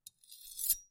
На этой странице собраны звуки микроскопа — от щелчков регулировки до фонового гула при работе.
Звук: нанесли жидкий состав на предметное стекло микроскопа